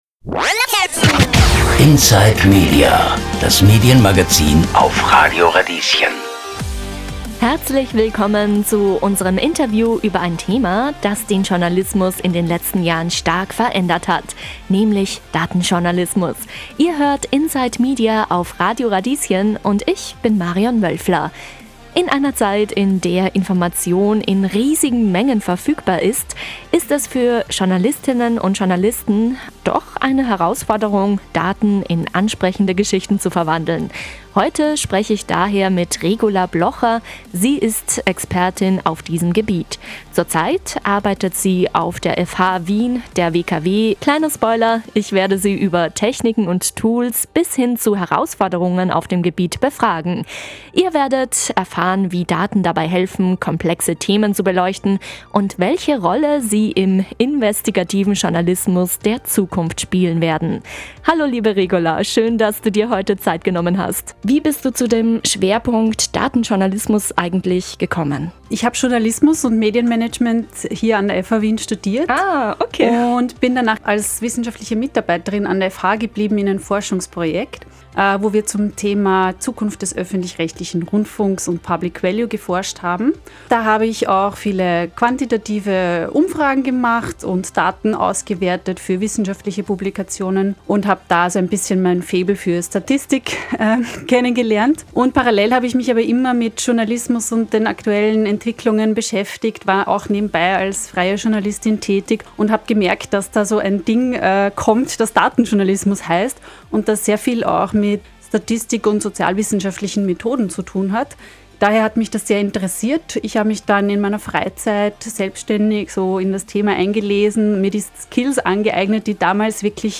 Inspiration für angehende Journalist:innen Ob ihr bereits im Journalismus arbeitet oder euch für datengetriebene Medien interessiert – dieses Interview bietet Inspiration pur.